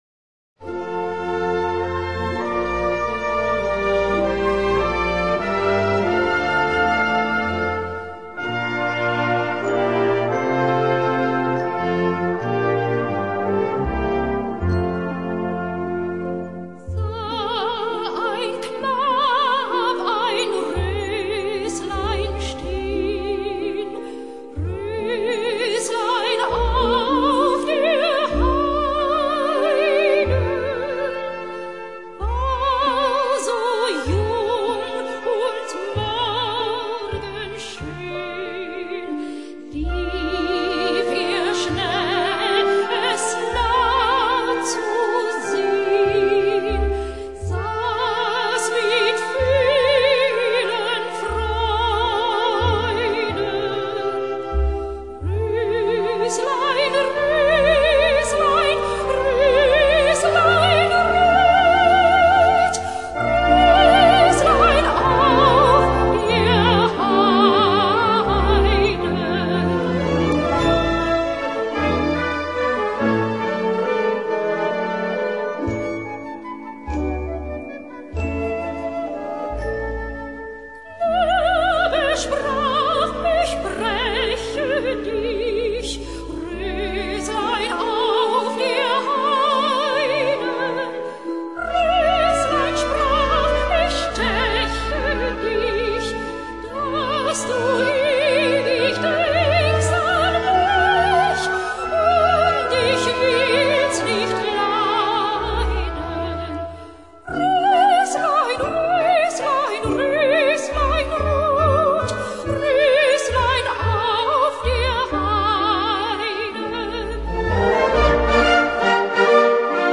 für Solo Gesang und Blasorchester Schwierigkeit
2:38 Minuten Besetzung: Blasorchester Zu hören auf